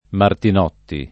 Martinotti [ martin 0 tti ] cogn.